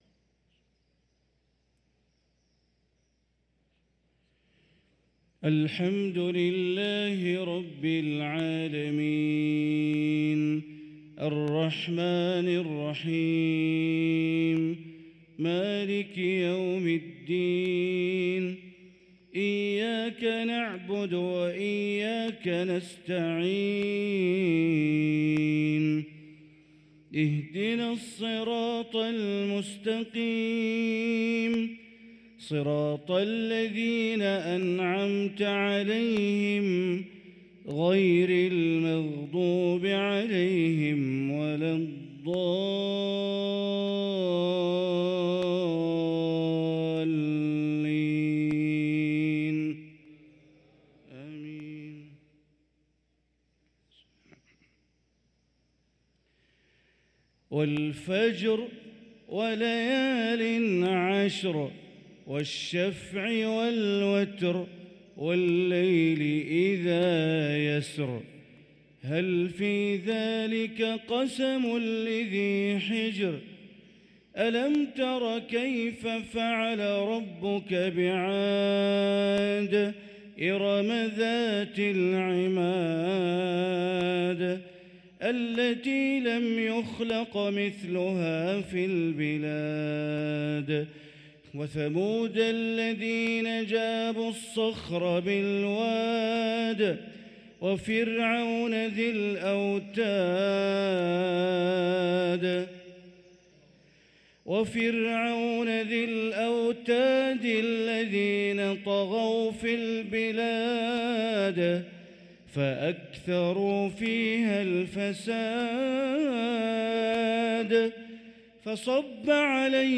صلاة المغرب للقارئ بندر بليلة 28 جمادي الآخر 1445 هـ